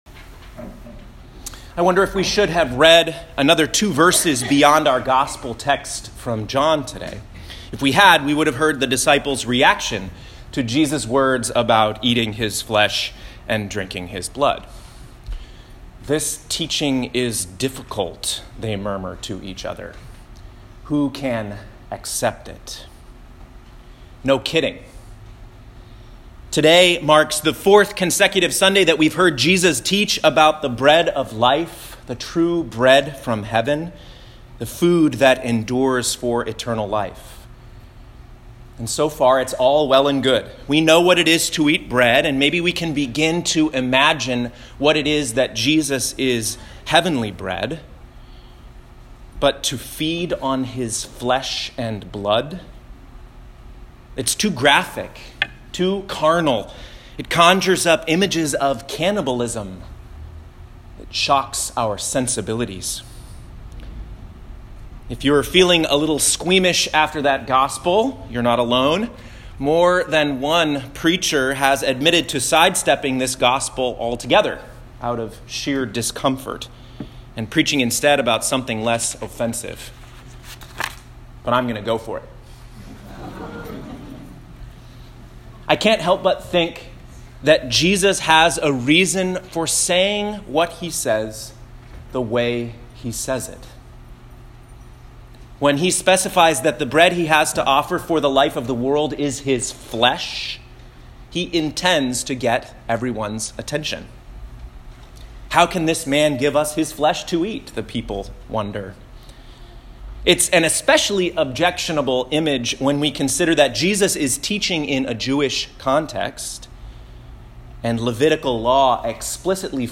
Thirteenth Sunday after Pentecost, Year B (8/19/2018) Proverbs 9:1-6 Psalm 34:9-14 Ephesians 5:15-20 John 6:51-58 Click the play button to listen to this week’s sermon.